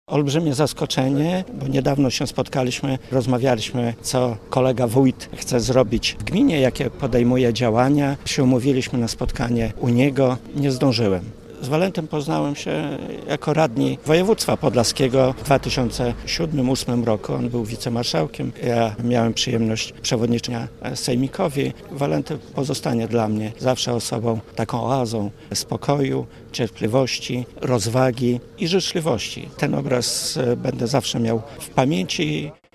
Ogromne zaskoczenie i wielka strata dla regionu - tak o śmierci Walentego Koryckiego mówi Radiu Białystok członek zarządu województwa podlaskiego Bogdan Dyjuk.